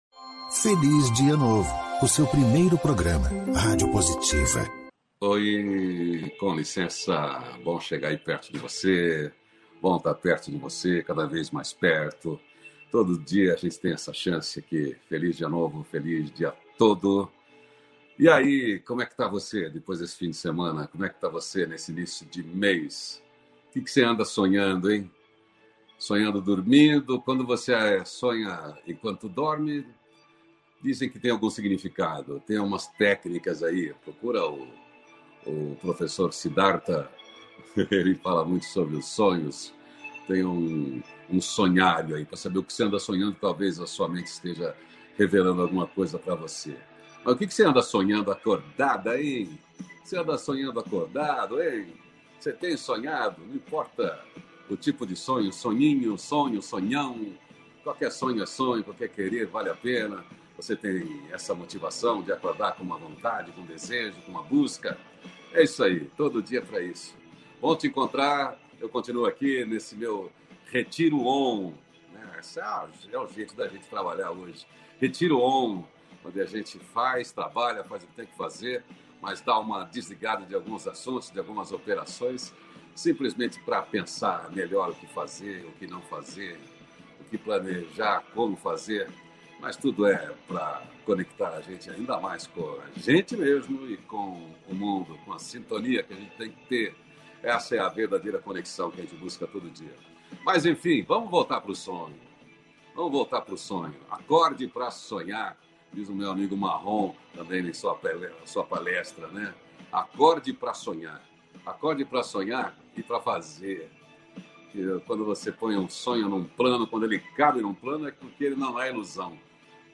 diálogo nutritivo